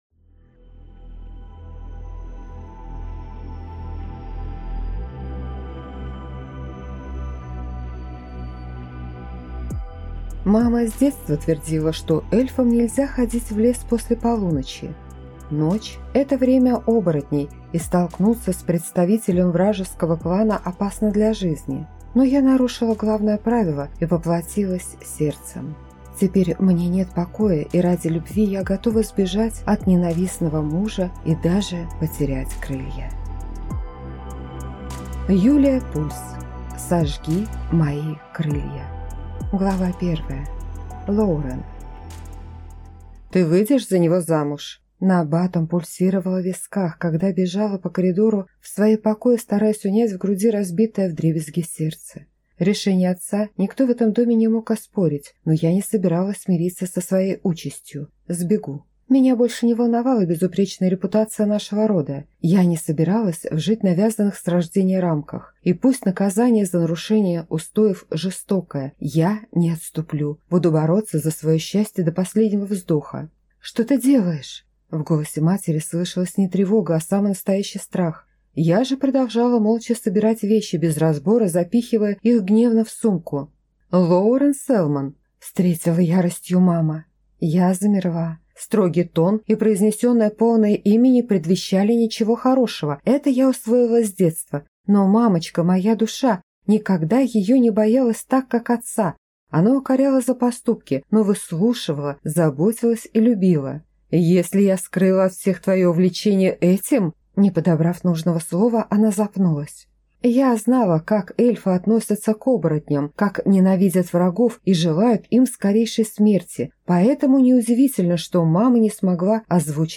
Aудиокнига Сожги мои крылья